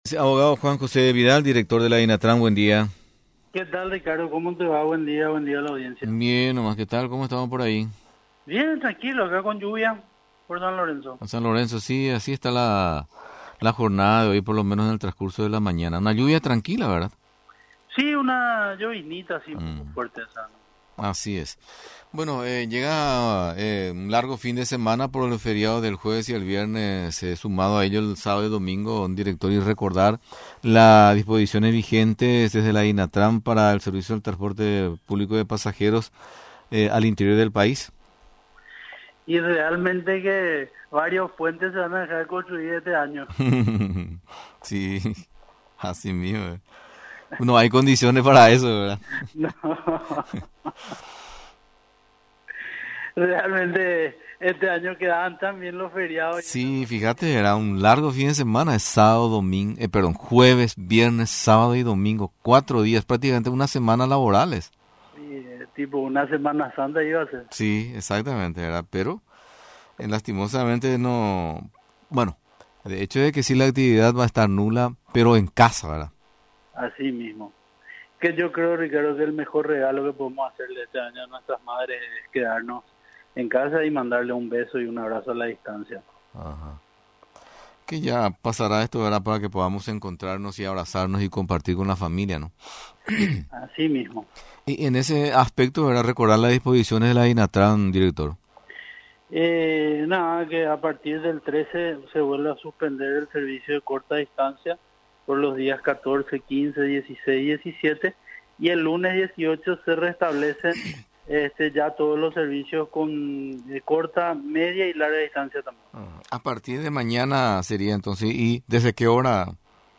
El Director de Dinatran, Juan José Vidal, habló del nuevo paro que se realizará desde el jueves 14, hasta el 17 de mayo. Al día siguiente, se restablecen ya todos los servicios públicos, sean cortas, medianas y largas distancias. La salvedad es que se trabajará, solamente con un 50 por ciento de las frecuencias normales.